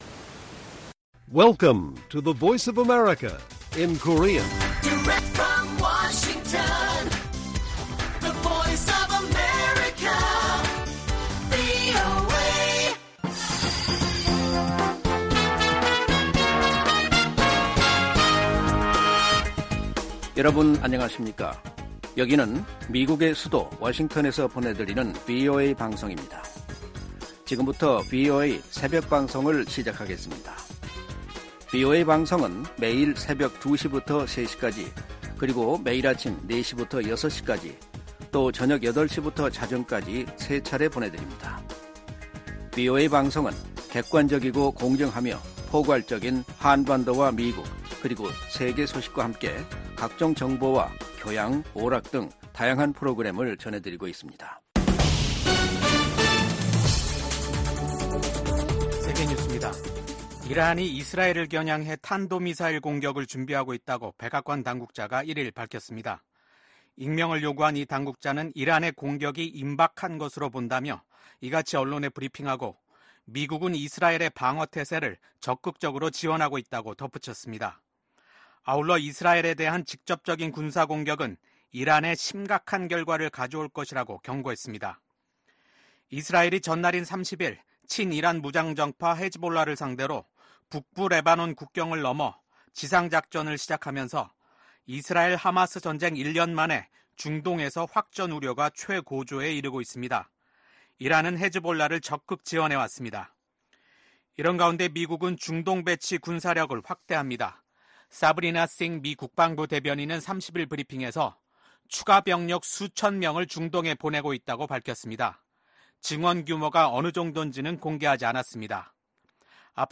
VOA 한국어 '출발 뉴스 쇼', 2024년 10월 2일 방송입니다. 윤석열 한국 대통령은 북한이 핵무기를 사용하려 한다면 정권 종말을 맞게 될 것이라고 경고했습니다. 김성 유엔주재 북한 대사가 북한의 핵무기는 자위권을 위한 수단이며 미국과 핵 문제를 놓고 협상하지 않겠다고 밝혔습니다. 신임 일본 총리 예정자가 ‘아시아판 나토’ 창설 필요성을 제기한 가운데, 백악관은 기존 북대서양조약기구 강화에 주력하고 있음을 강조했습니다.